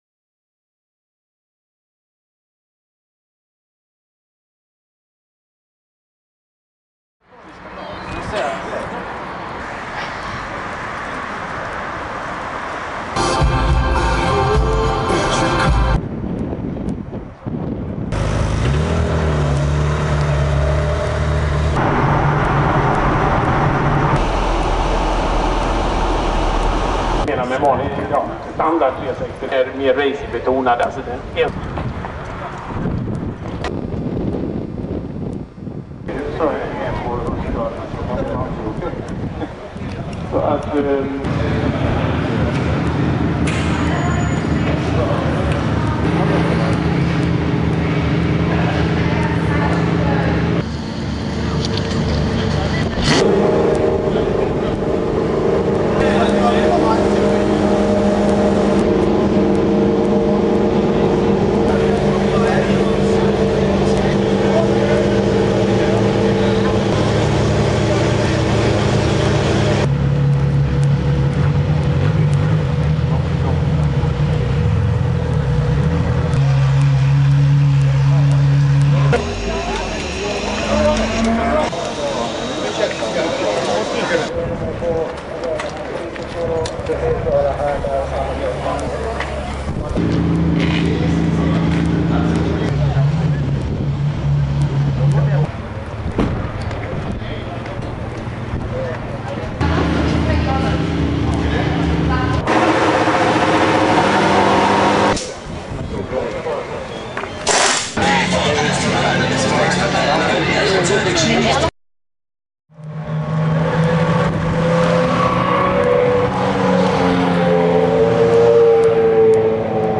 Det h�lls p� Mantorp park och schemat var relativt sp�ckat. Vad s�gs om tre stycken Ferrari Formula 1 bilar p� banan, svensk premi�rvisning av Ferrari Enzo samt 360 Challenge Stradale p� bana OCH tv� race av Ferrari Challengeklassen... Klicka h�r f�r att ladda ned en egengjord 20 min l�ng film fr�n evenemanget!